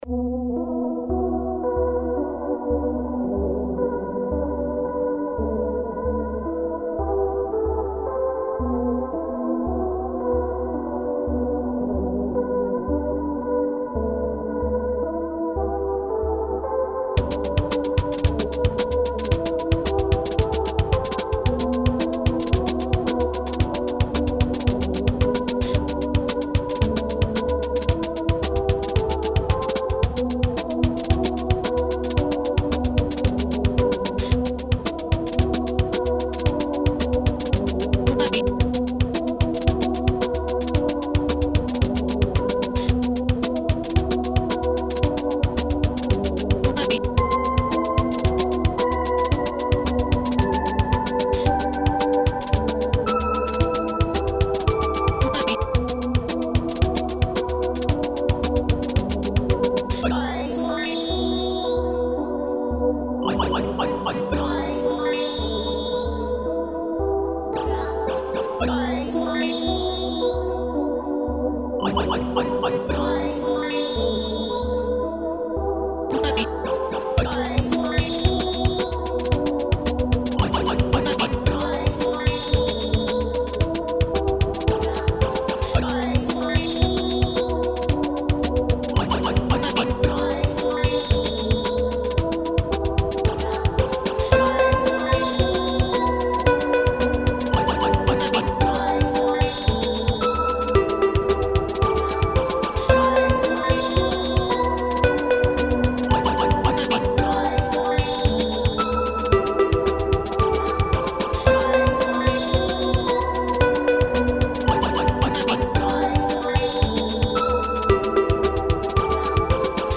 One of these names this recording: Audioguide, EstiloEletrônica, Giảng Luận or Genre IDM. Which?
Genre IDM